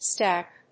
音節stacc.